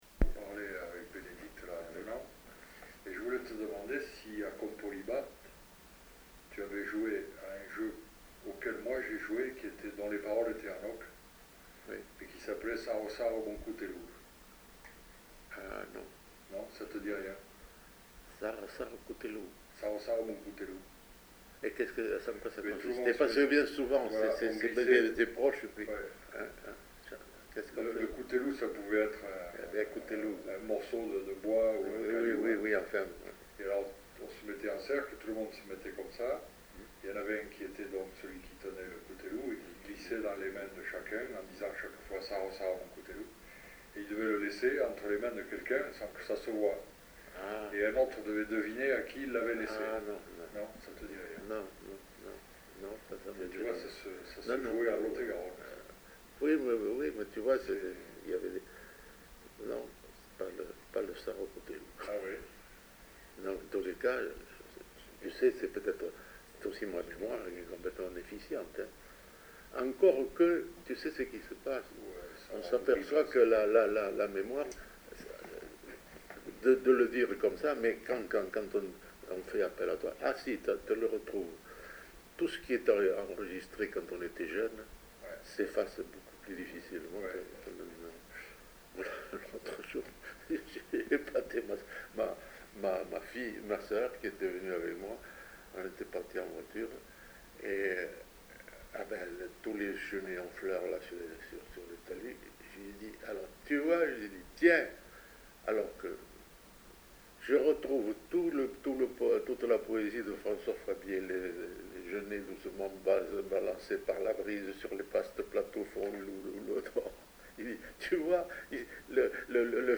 Lieu : Saint-Sauveur
Genre : témoignage thématique